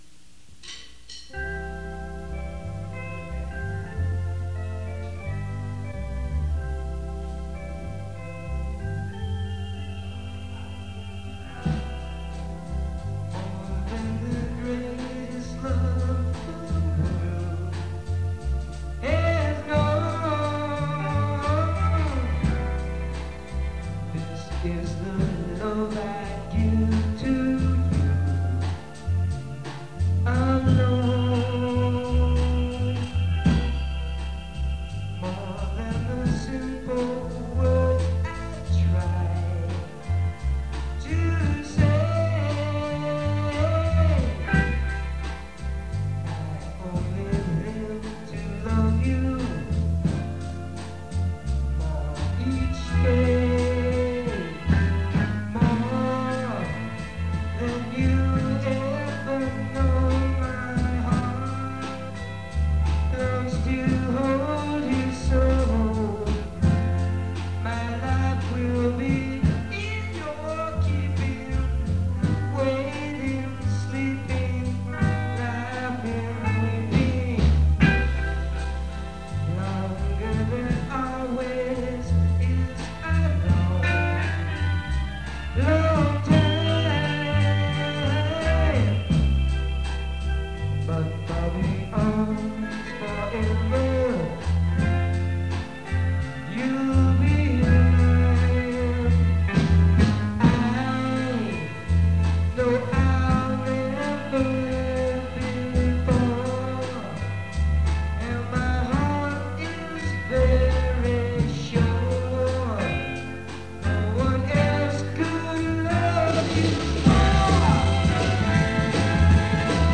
Listen to the practice recordings...unbelievable, I was just 17 yrs old.